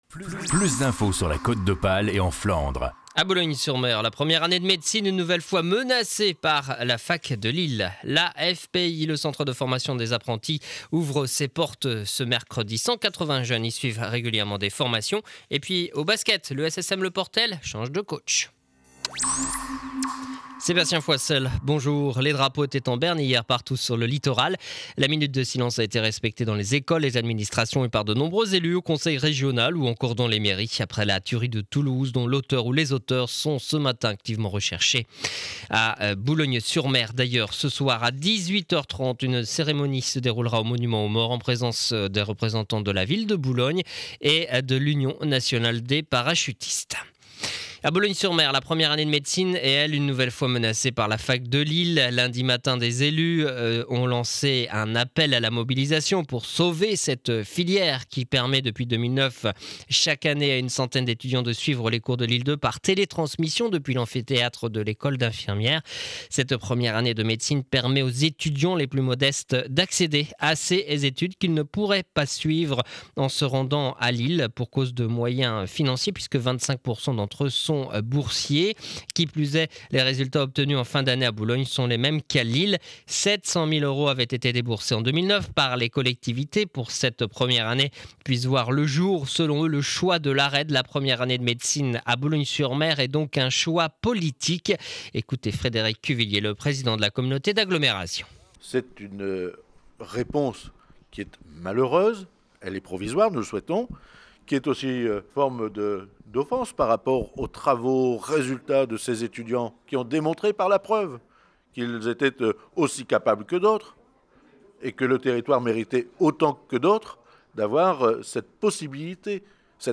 Journal du mercredi 21 mars 2012 7 heures 30 édition du Boulonnais.